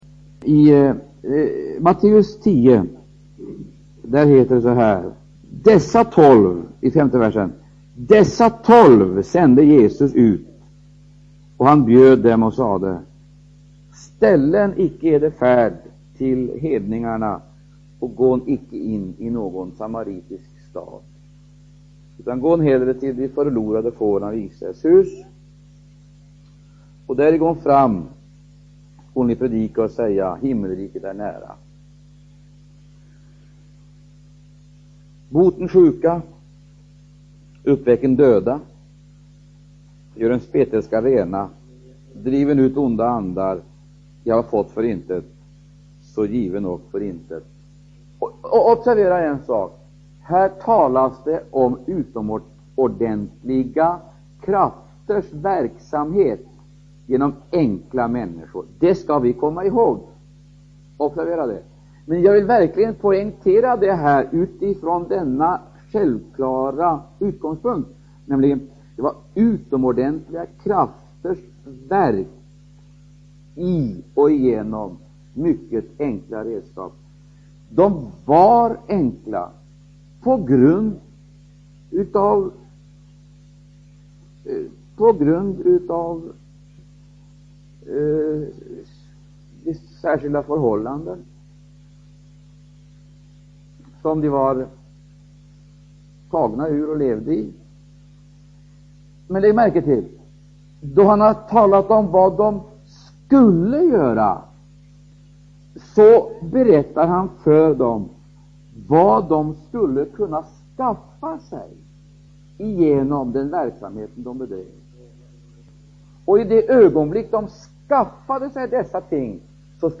Undervisning